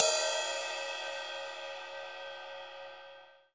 CYM RIDE300L.wav